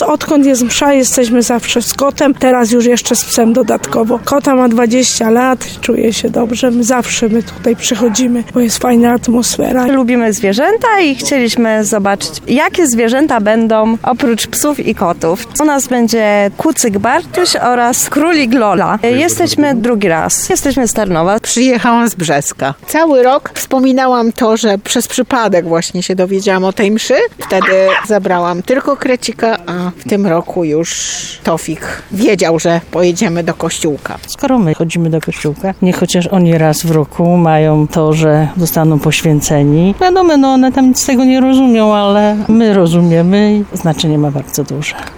-mówiła mieszkanka Tarnowa z parafii katedralnej.
-dodawała kobieta, która na Mszę przyjechała z dwójką pinczerków.